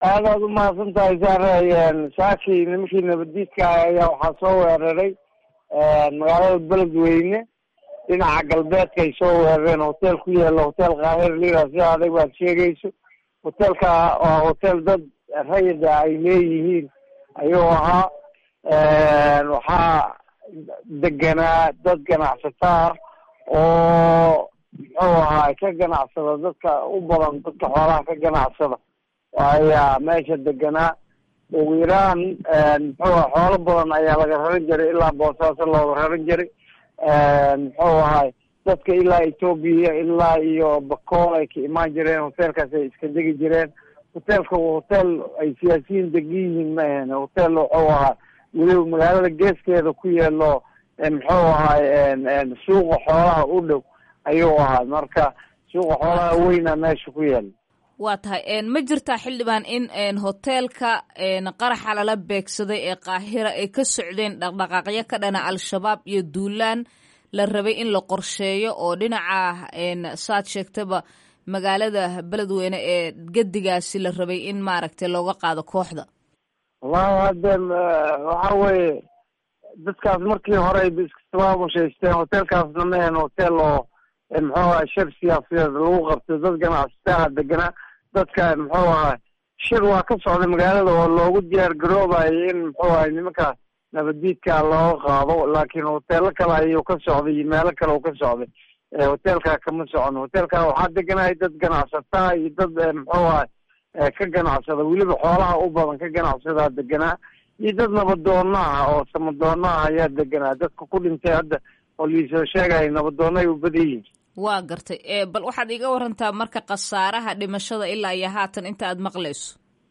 Wareysi: Daahir Amiin Jeesow